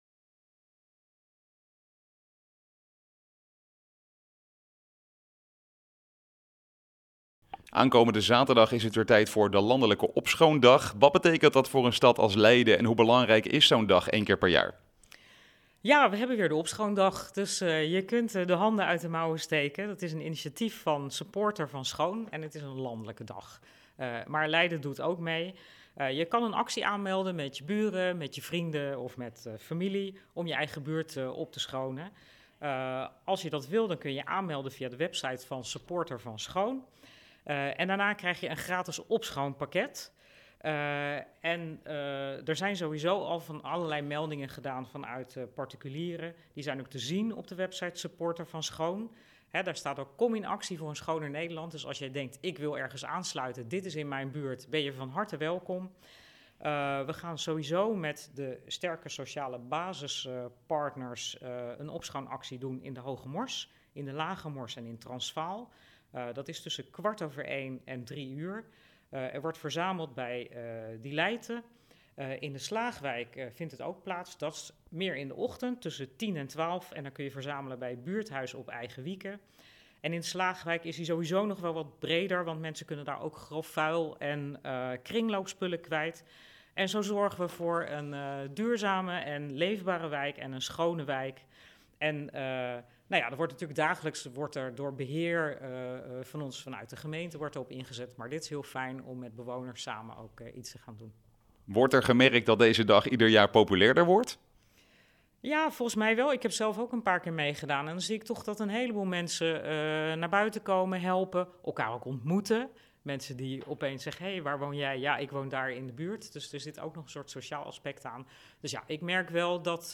gaat in gesprek met wethouder Yvonne van Delft over de schoonmaak dag